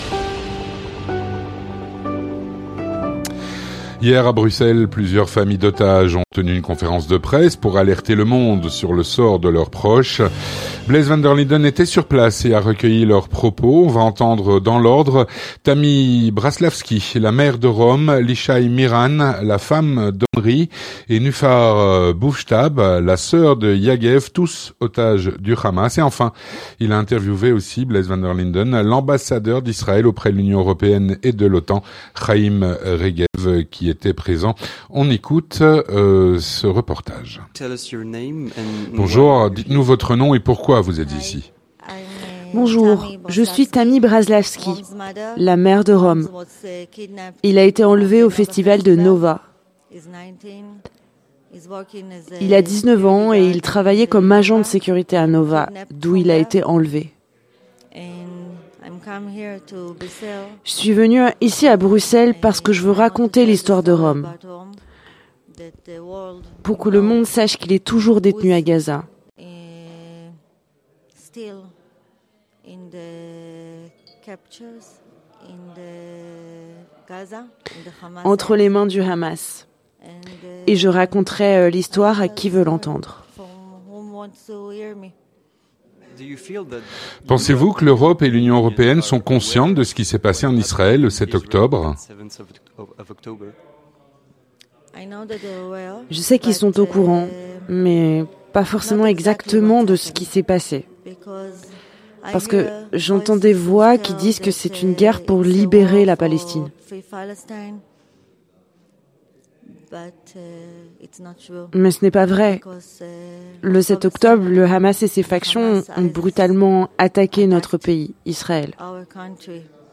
Témoignage - À Bruxelles, plusieurs familles d’otages ont tenu une conférence de presse pour alerter le monde sur le sort de leurs proches.
À Bruxelles, plusieurs familles d’otages ont tenu une conférence de presse pour alerter le monde sur le sort de leurs proches.